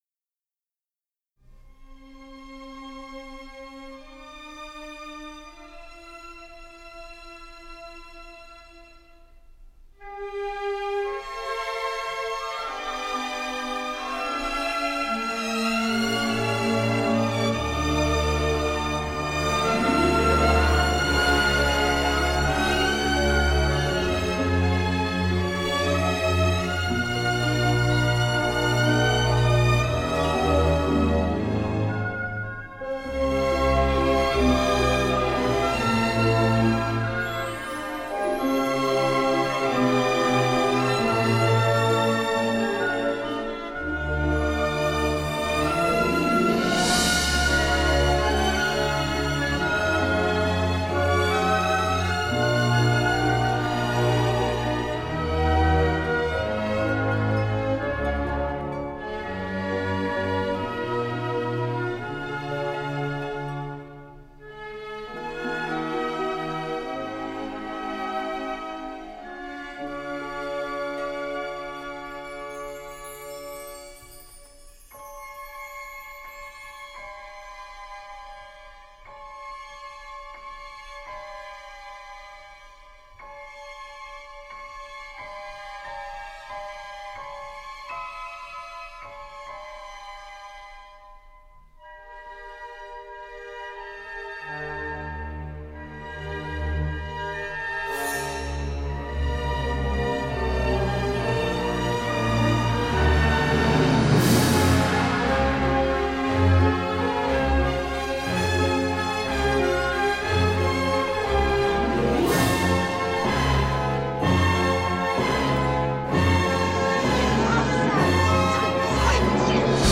Narrateur : Quelques années plus tard